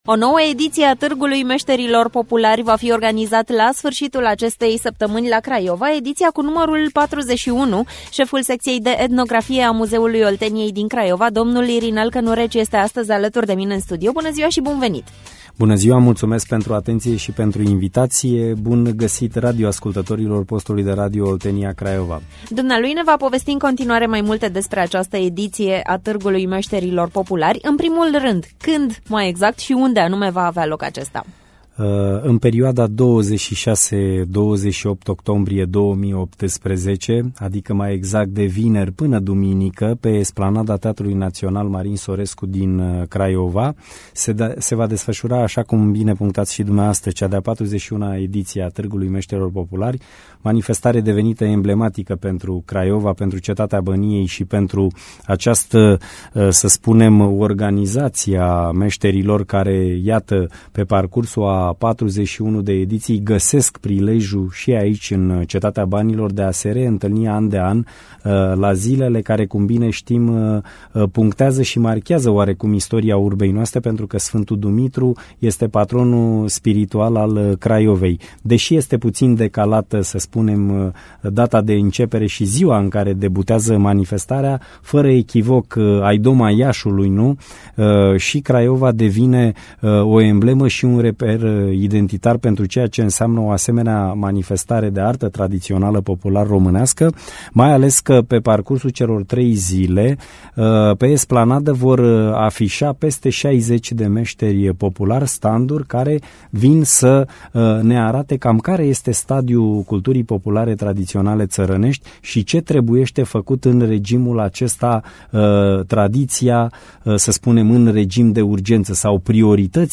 Invitat la emisiunea Pulsul Zilei